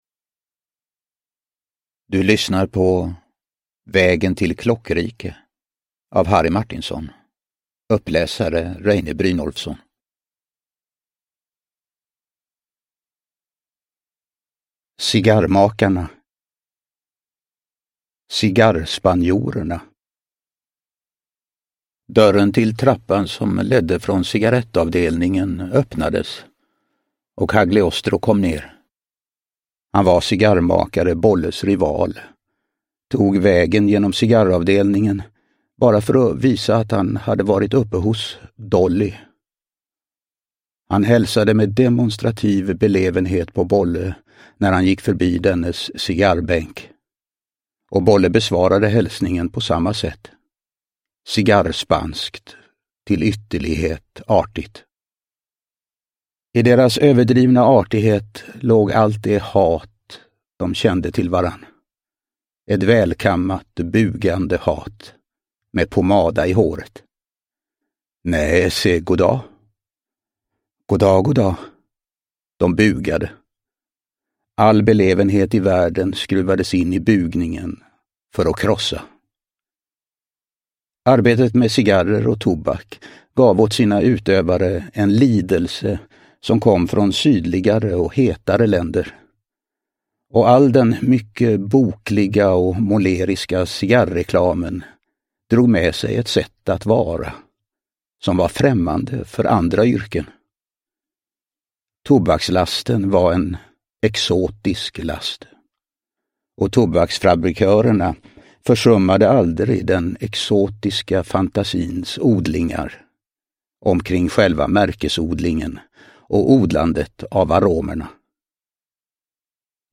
Uppläsare: Reine Brynolfsson
Ljudbok